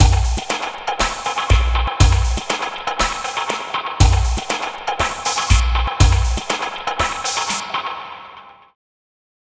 Astro 2 Drumz Wet.wav